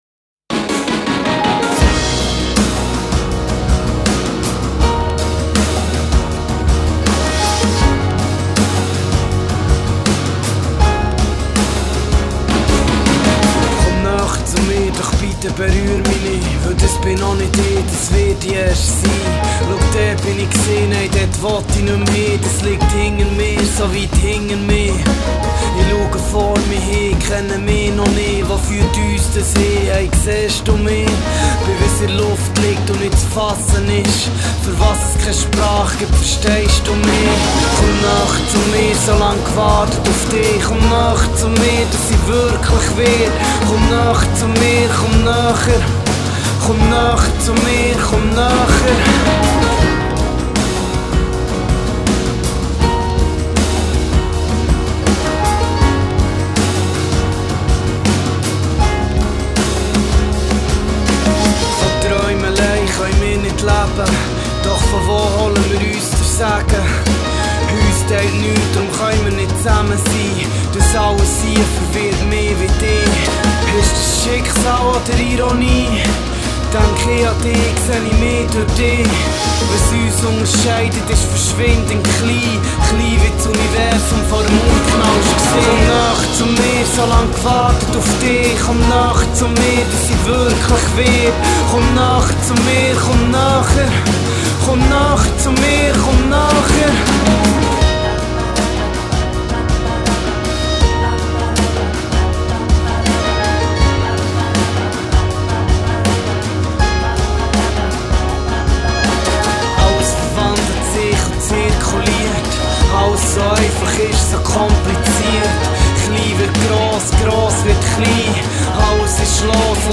Poetry / Rap / Pop.
vernacular freestyle rapper
a relaxed pop attitude and mainstream character